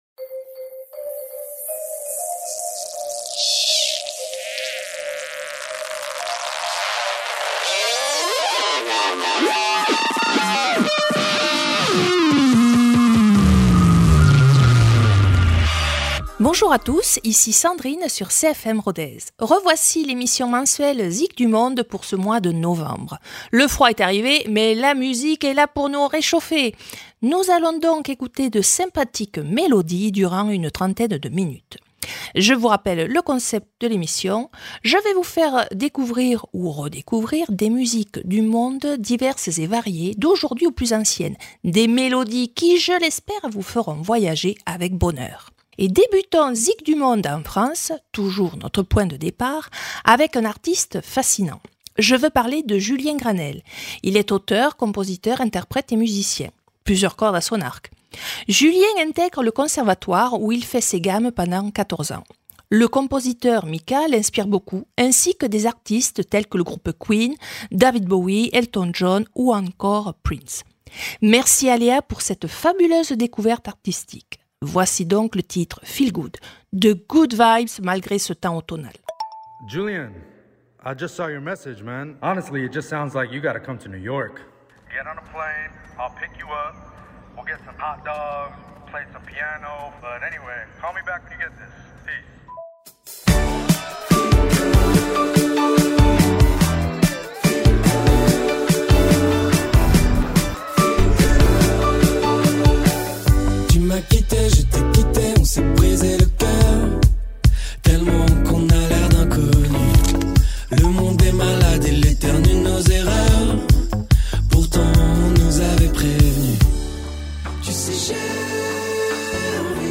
Toujours en musique, voici un tour du monde qui réchauffera les cœurs comme un bon chocolat chaud.